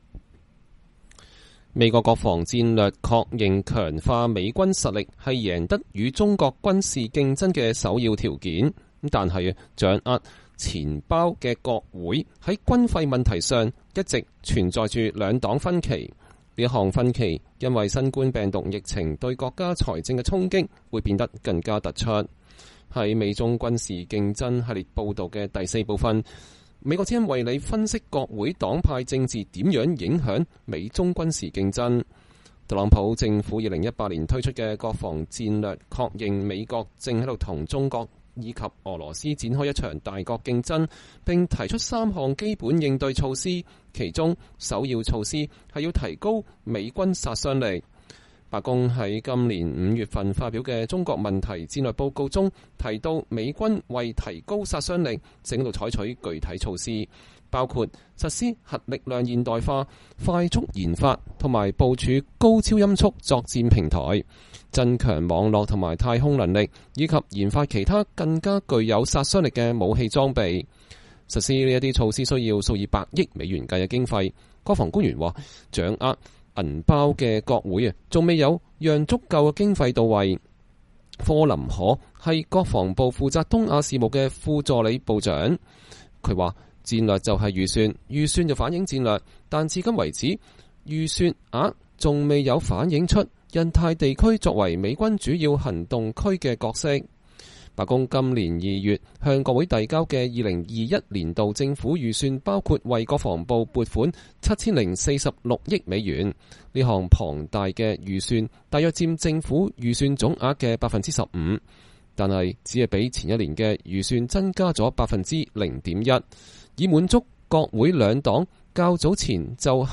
在《美中軍事競爭》系列報導的第四部分，美國之音為您報導國會黨派政治正如何影響美中軍事競爭。